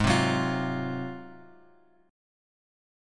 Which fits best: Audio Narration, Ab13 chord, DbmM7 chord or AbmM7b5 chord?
Ab13 chord